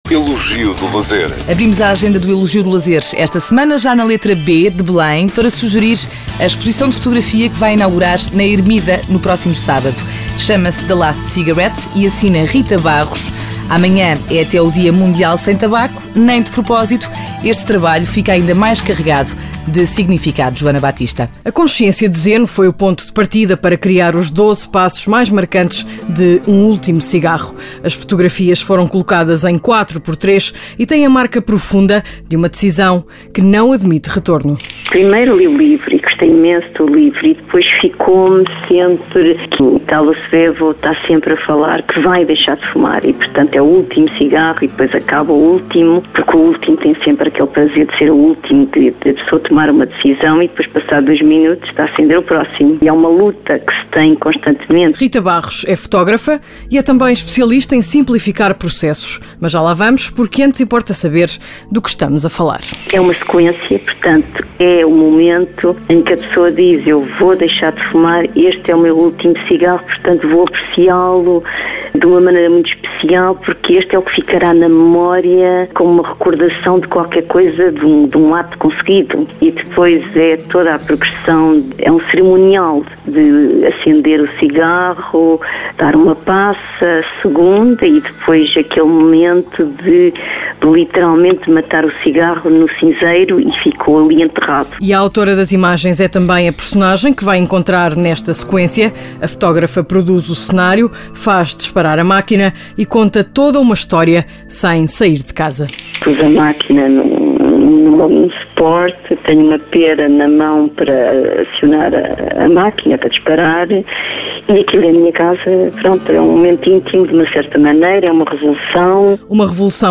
Radio Clube Português- Interview (Portuguese)